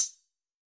Index of /musicradar/ultimate-hihat-samples/Hits/ElectroHat C
UHH_ElectroHatC_Hit-23.wav